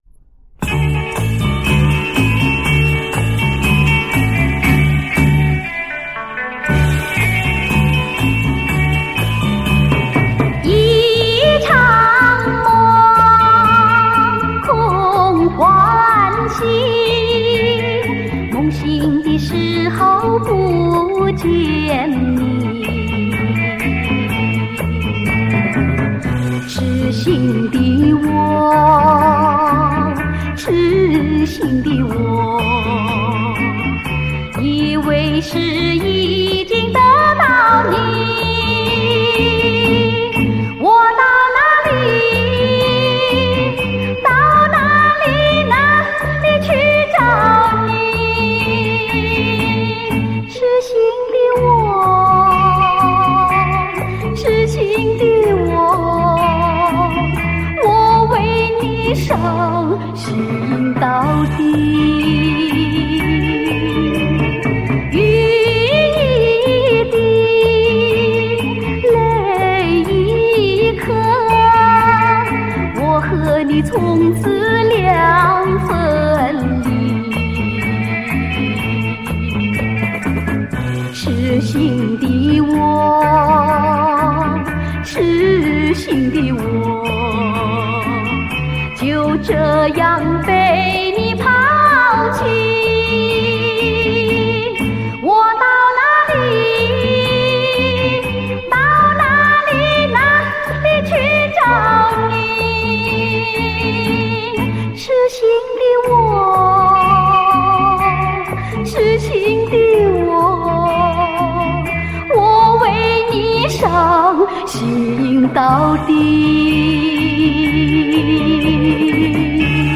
LP